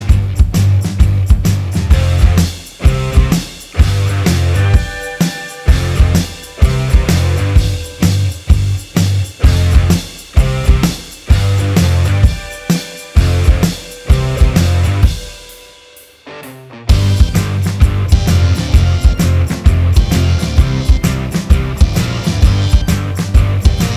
Minus All Guitars Indie / Alternative 3:46 Buy £1.50